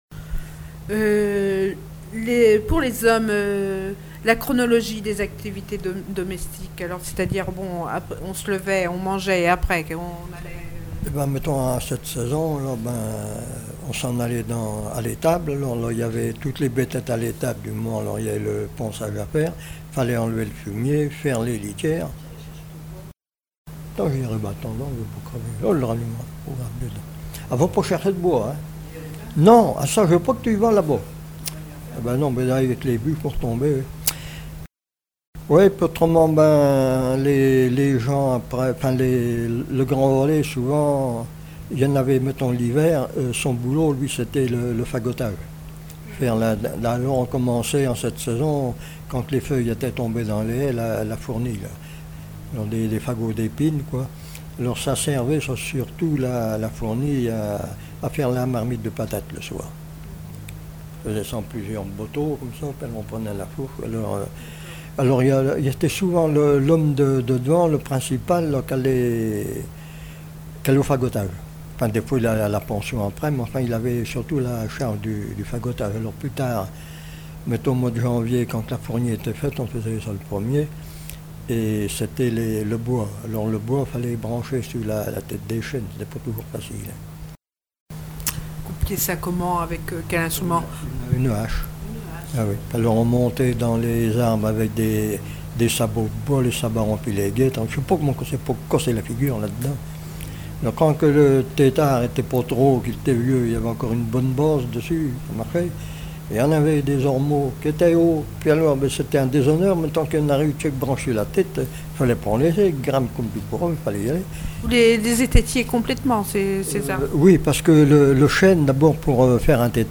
Témoignages de vie
Catégorie Témoignage